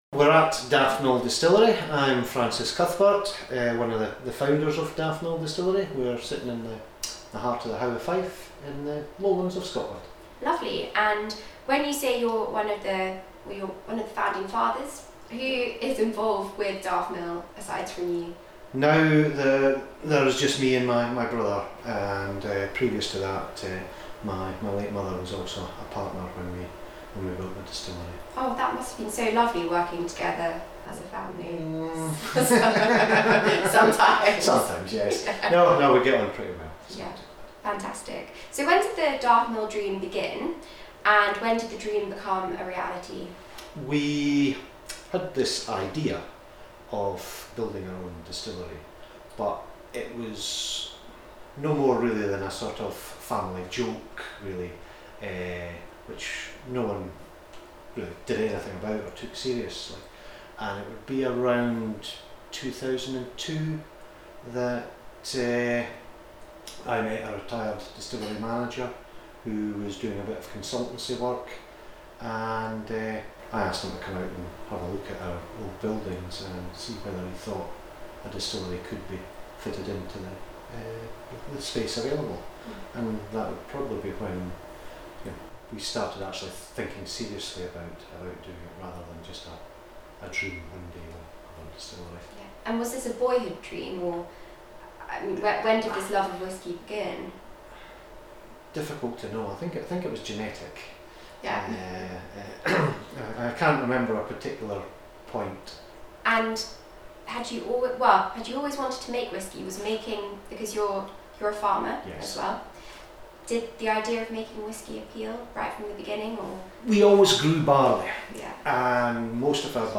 Daftmill Interview